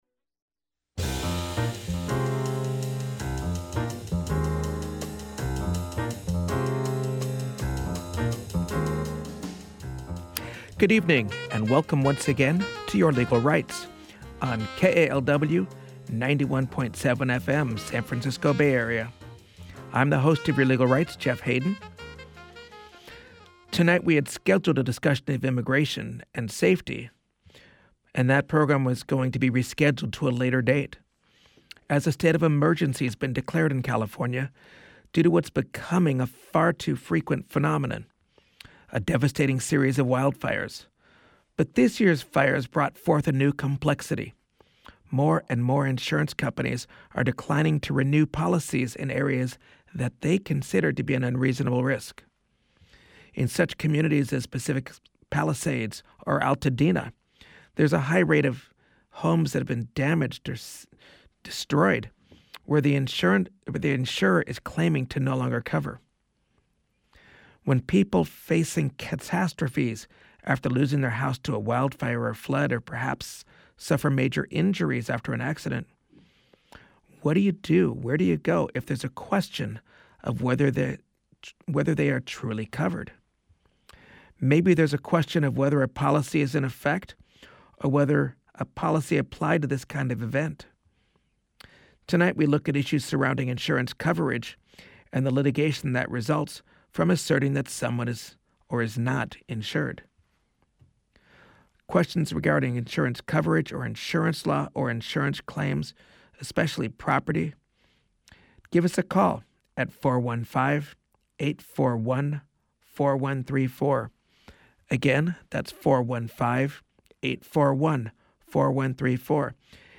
talks with experts on various legal topics, with listener participation.